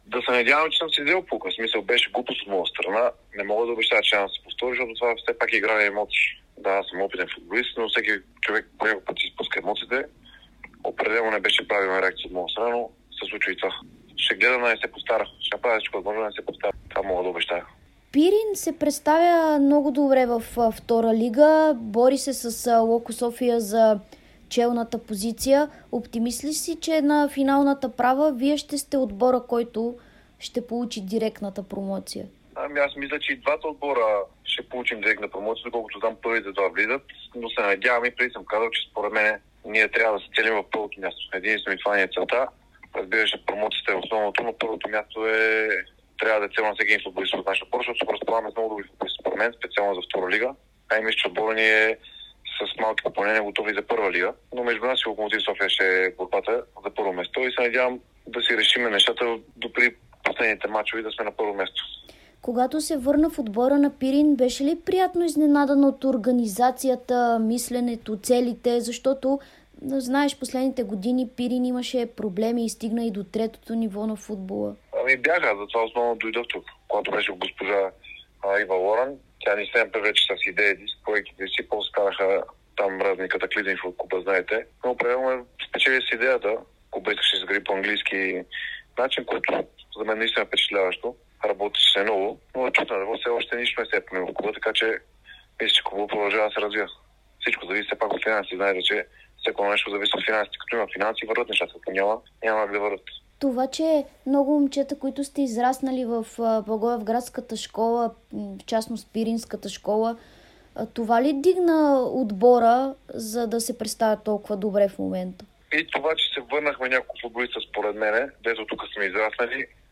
Защитникът на Пирин Благоевград Николай Бодуров говори пред Дарик и dsport в деня, в който му изтича наказанието от седем мача, което получи след като си изпусна нервите по време на срещата между "орлите" и Хебър Пазарджик.